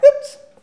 oups_03.wav